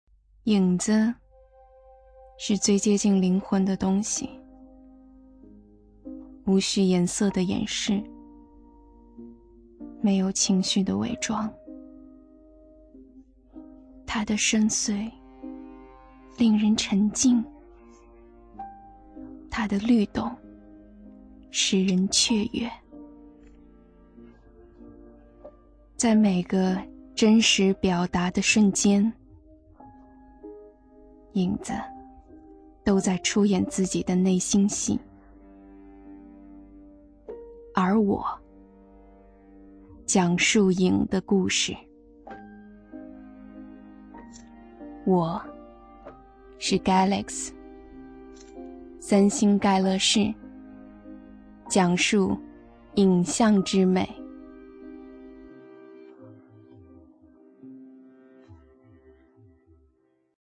【女58号广告】手机广告（性冷淡 旁白）